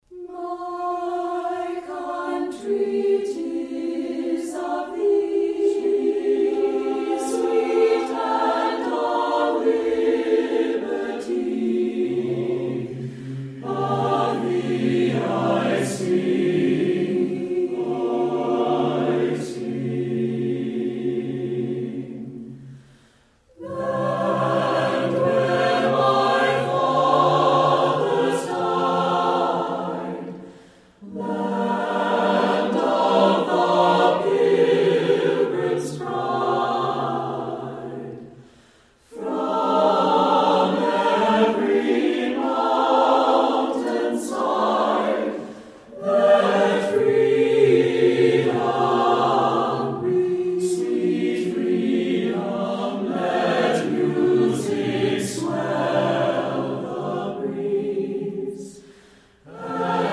Performers: U.S. Army Chorus